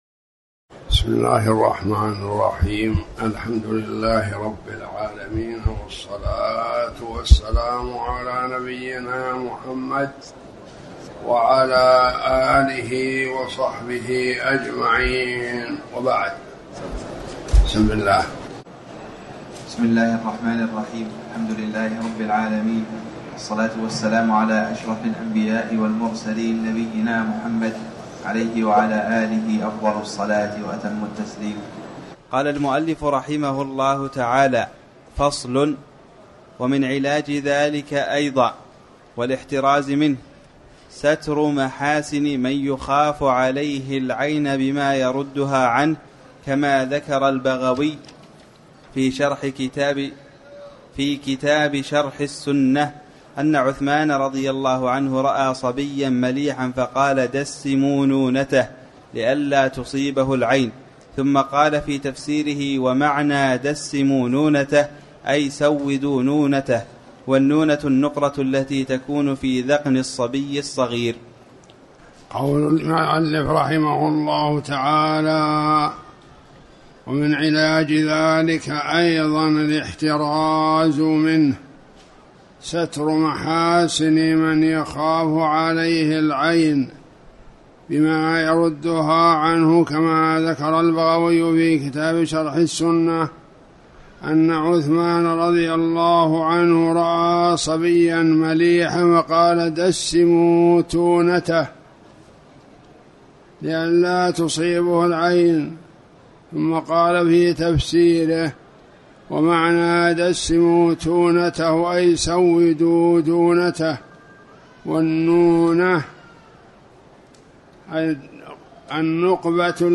تاريخ النشر ١٤ ذو القعدة ١٤٣٩ هـ المكان: المسجد الحرام الشيخ